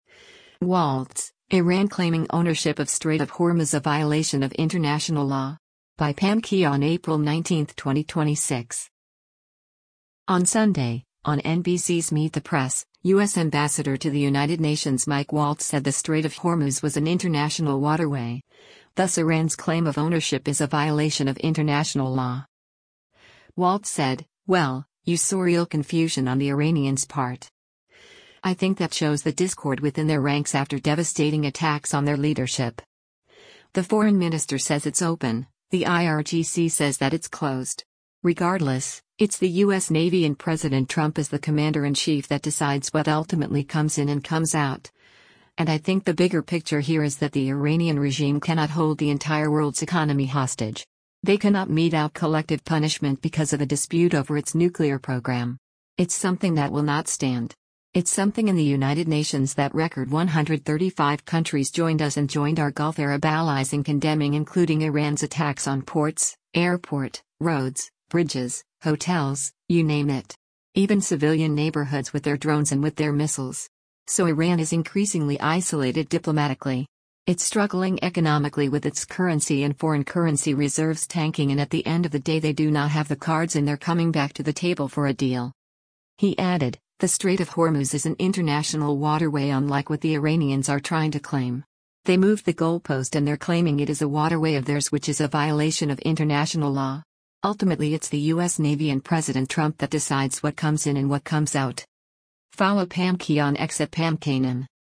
On Sunday, on NBC’s “Meet the Press,” U.S. Ambassador to the United Nations Mike Waltz said the Strait of Hormuz was an international waterway, thus Iran’s claim of ownership “is a violation of international law.”